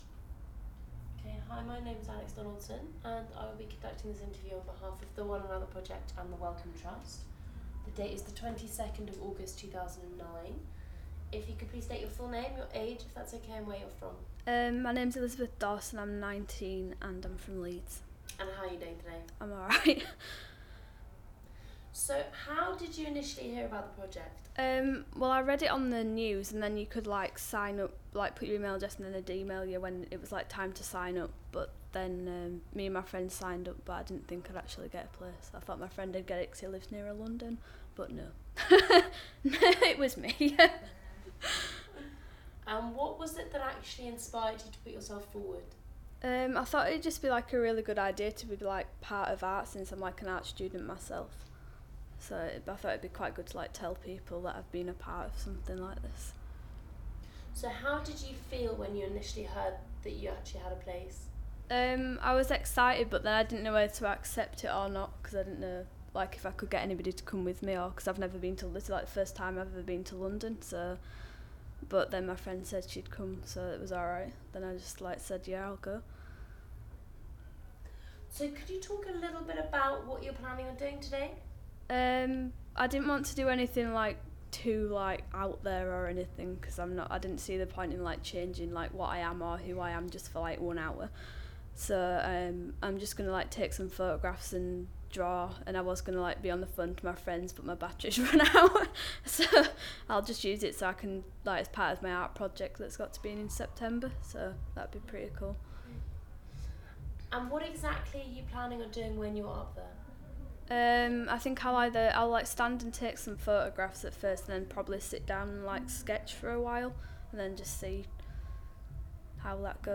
Audio file duration: 00:09:37 Format of original recording: wav 44.1 khz 16 bit ZOOM digital recorder.